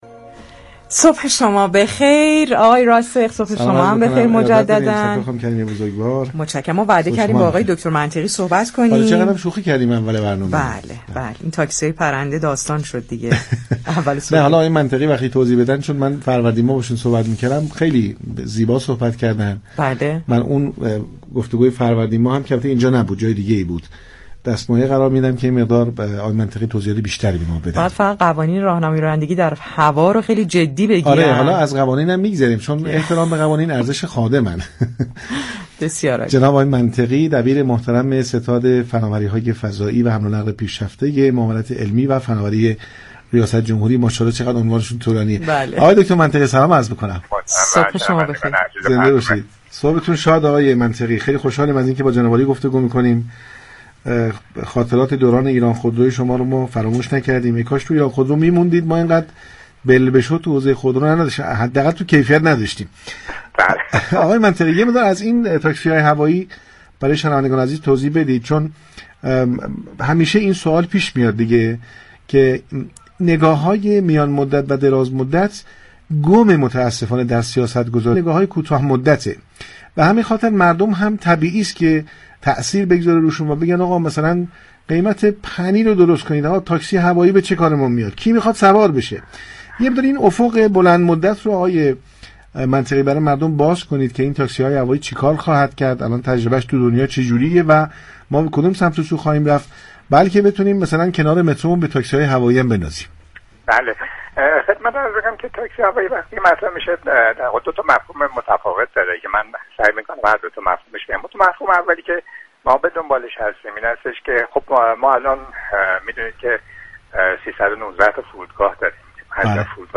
منوچهر منطقی در گفتگو با برنامه پارك شهر گفت: 319 فرودگاه نظامی و غیر نظامی در ایران وجود دارد كه از این نظر در جهان رتبه 22 ام را داریم و تعداد فرودگاه های ما از كشورهای اسپانیا، ایتالیا، سوئیس، نروژ و حتی ژاپن نیز بیشتر است.